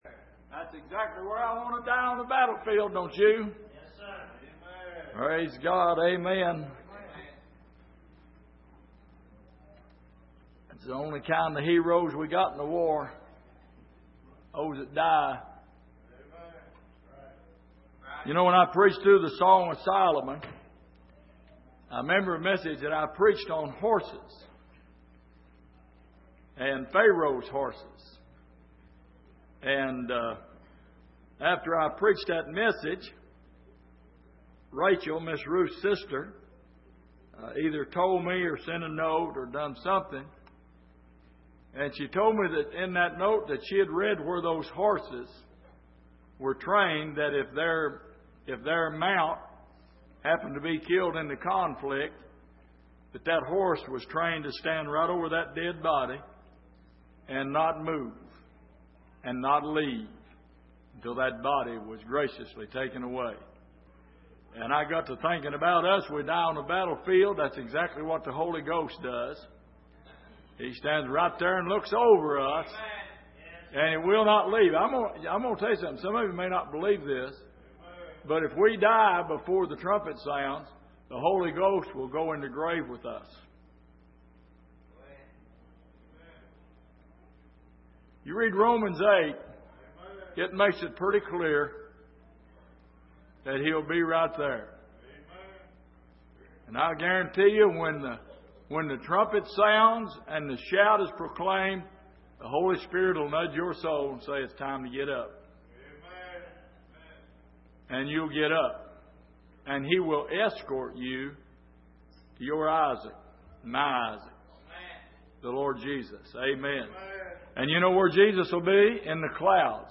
Peter's Life & I Peter Passage: 1 Peter 2:1-3 Service: Sunday Morning In The Beginning « How Well Do You Know Jesus?